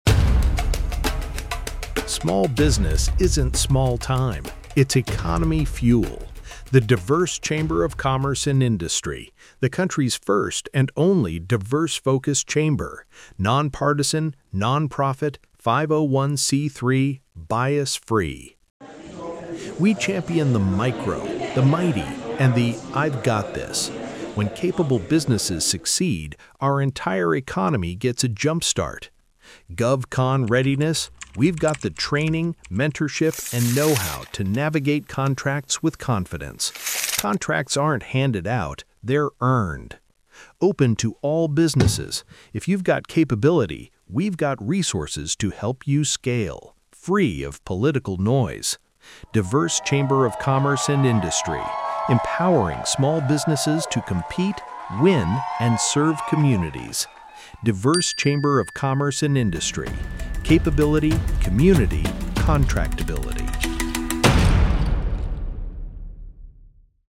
chamber-psa-podcast.mp3